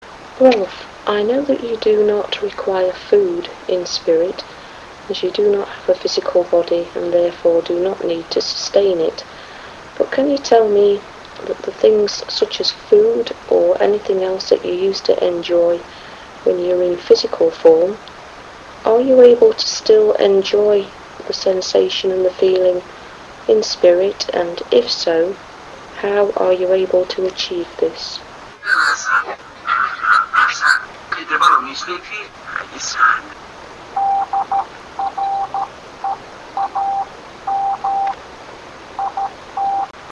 EVP MORSE – 21 – The Amazing Portal
Morse Reads: DREAM IT From much of my own research, I have come to the conclusion the afterlife is another quantum dimension whereby physical things are created by thought.
Don’t forget to listen out for Voice EVP.